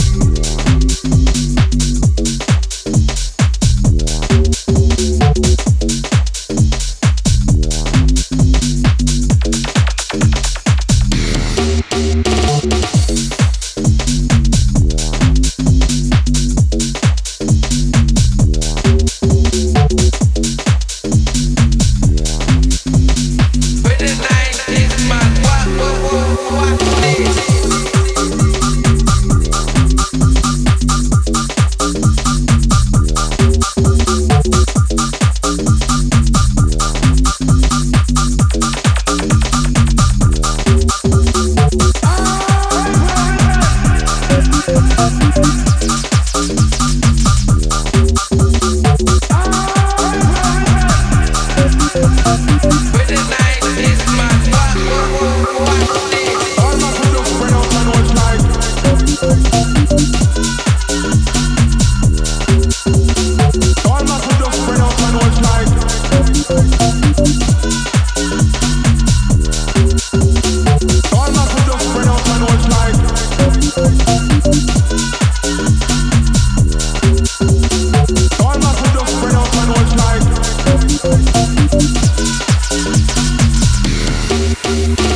serving up a killer cut of bumping 4x4 UKG goodness!